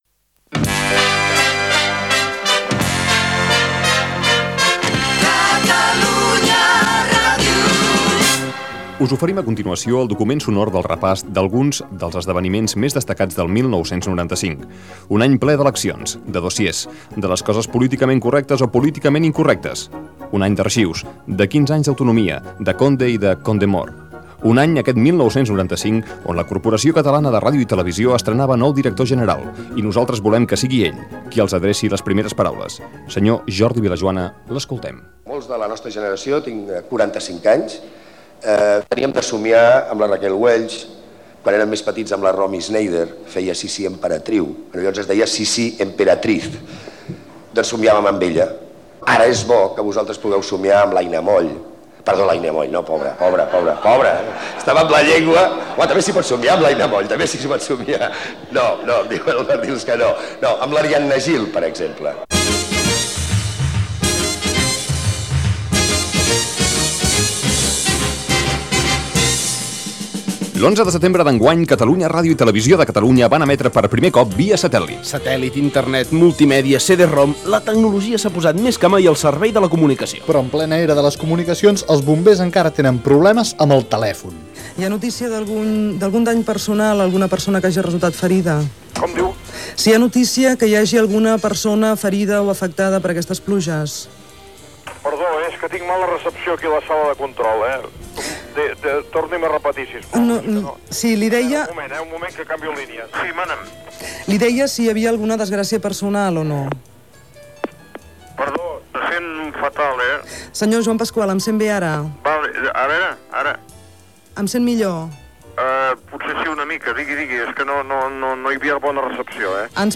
"Alguna Pregunta Més", resum de l'any 1995: Jordi Vilajoana, Pasqual Maragall, etc. Gènere radiofònic Entreteniment